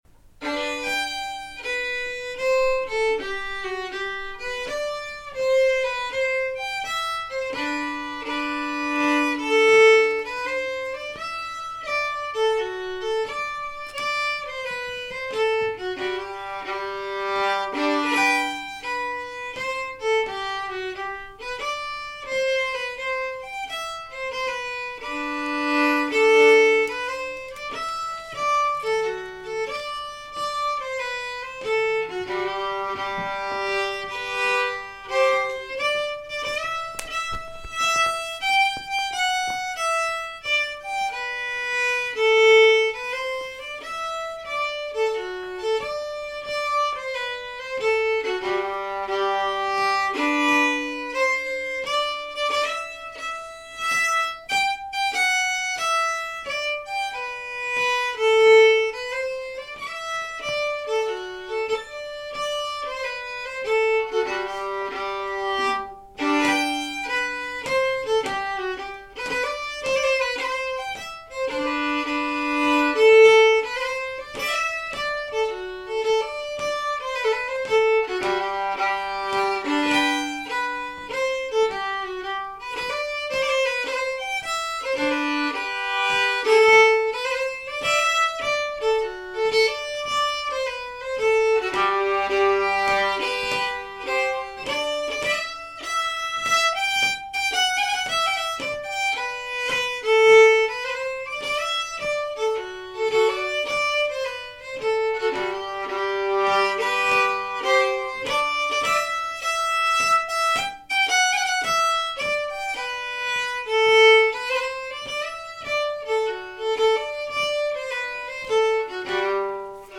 (slow, fast)